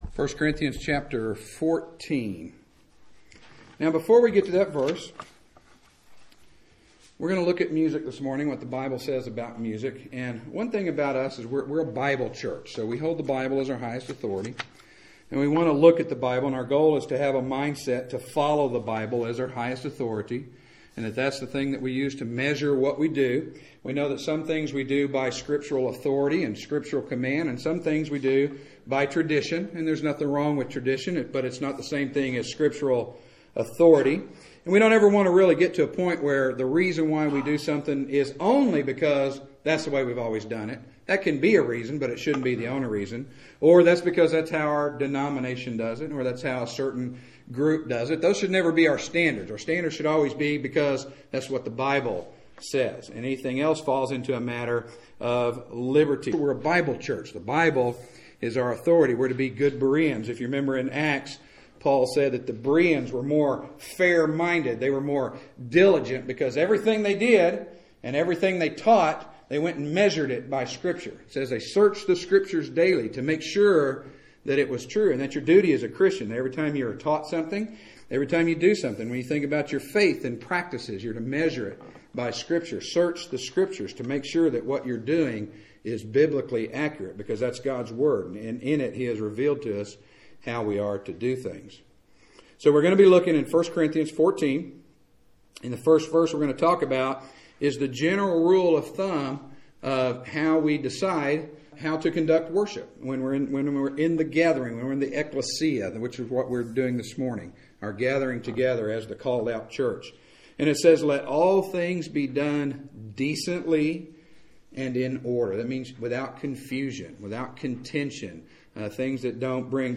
Preached at Straightway Bible Church on February 3rd, 2013.